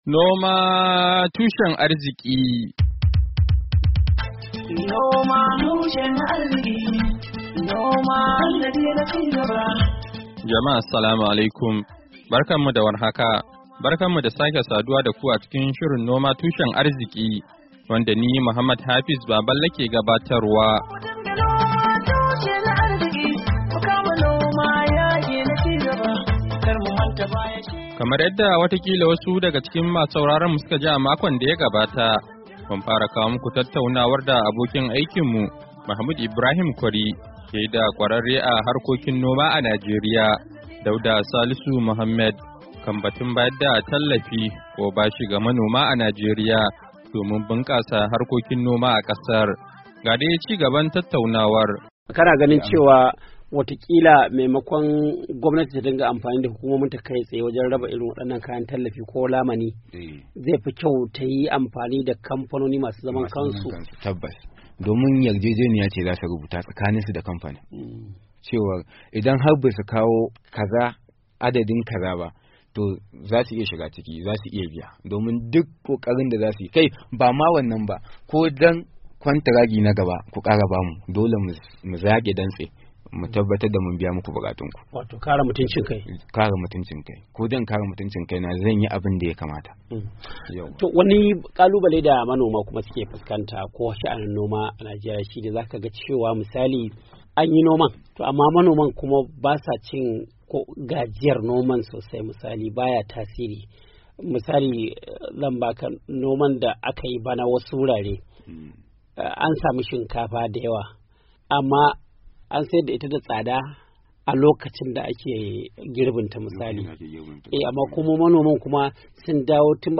NOMA TUSHEN ARZIKI: Hira Da Kwararre Kan Harkokin Noma A Kan Batun Bunkasa Noma A Najeriya - Kashin Na Hudu - Mayu 09, 2023